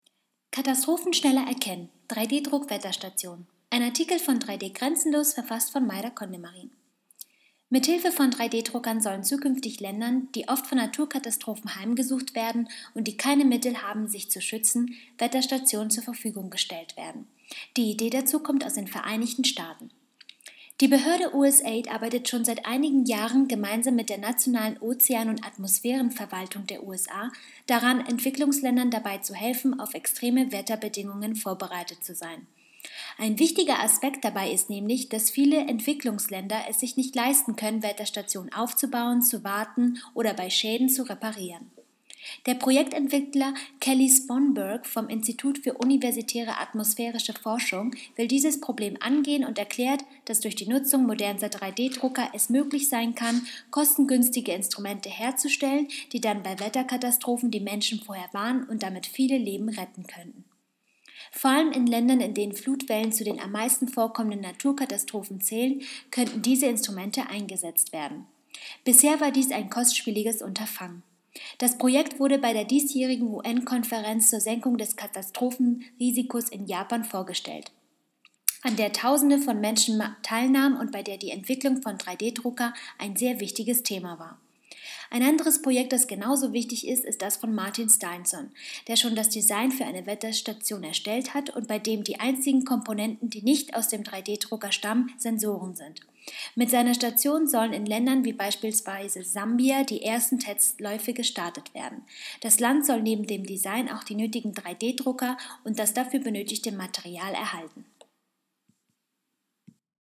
news_vorlesen_lassen_3d-druck-wetterstationen.mp3